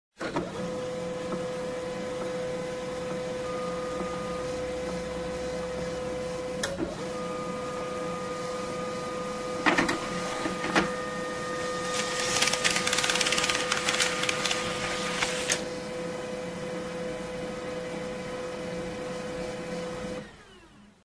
Звуки принтера
На этой странице собраны звуки работающего принтера: от монотонного жужжания лазерных моделей до характерного треска матричных устройств.